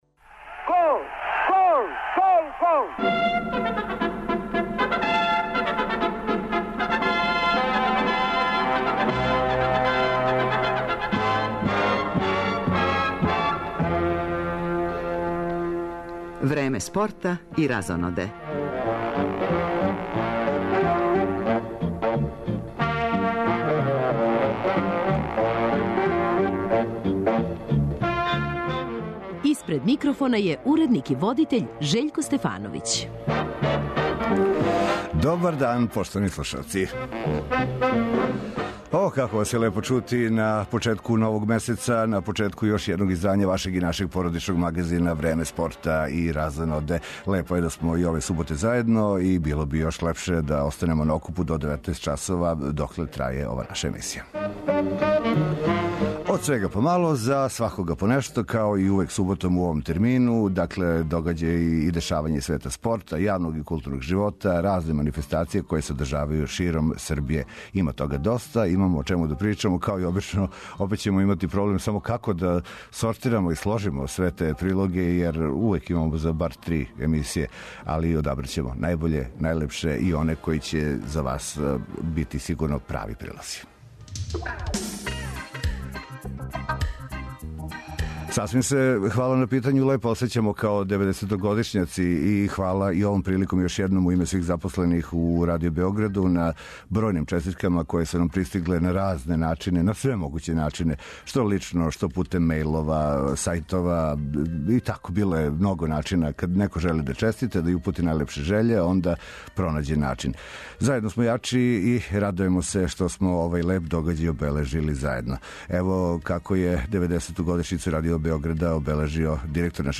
Данас су на реду одбојкашице, које од 17 часова играју против САД важан меч за пласман у саму завршницу првенства света, чућемо и коментаре тренера наших прволигашких клубова после сваког сета.